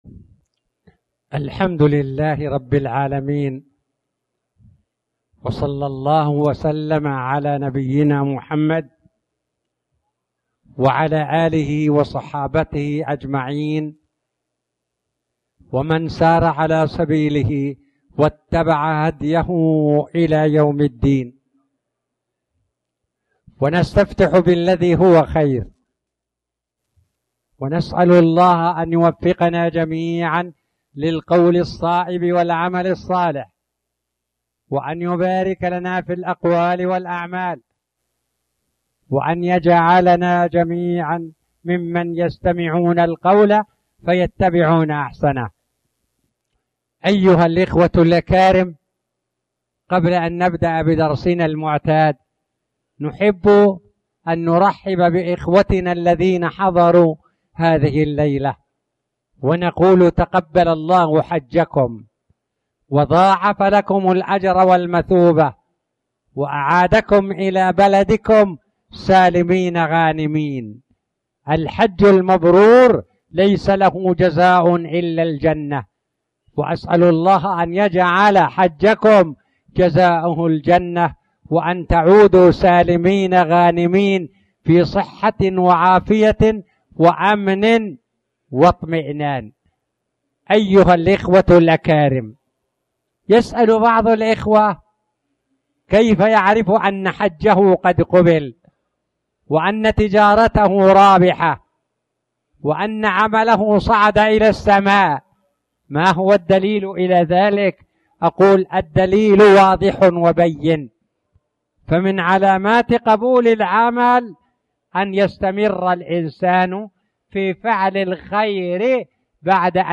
تاريخ النشر ٢٧ ذو الحجة ١٤٣٨ هـ المكان: المسجد الحرام الشيخ